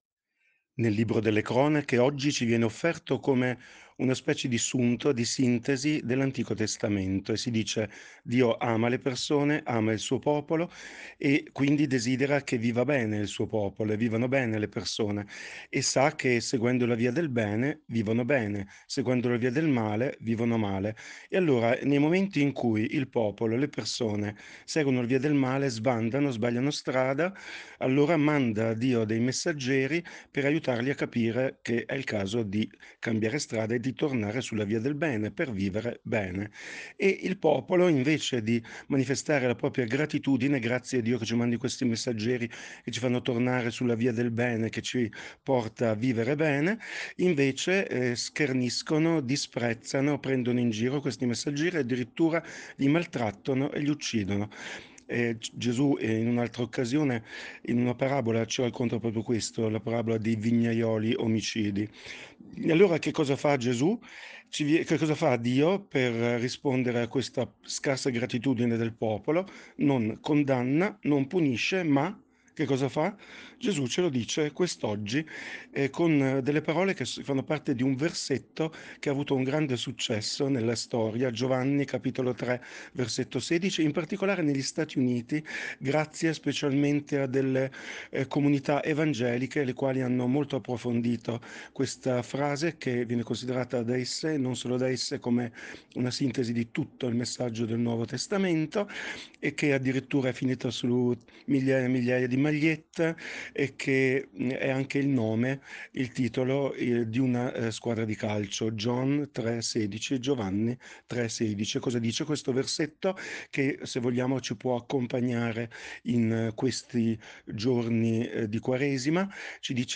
Meditazione Domenica 10 marzo 2024 – Parrocchia di San Giuseppe Rovereto